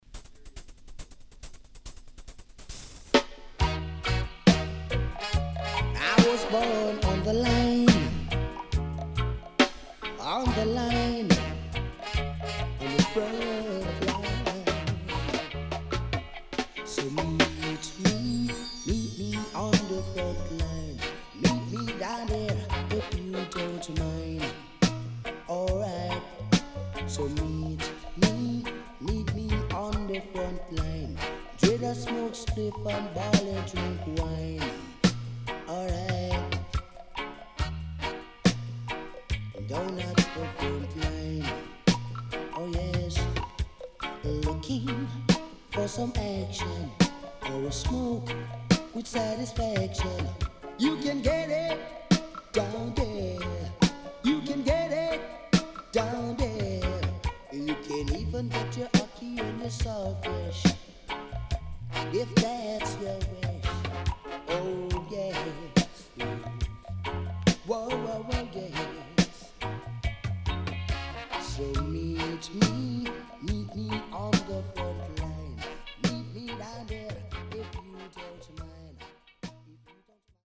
HOME > LP [DANCEHALL]  >  EARLY 80’s
全曲Dubwiseが収録されたShowcase Styleの傑作初期Dancehall Album
SIDE A:少しノイズ入りますが良好です。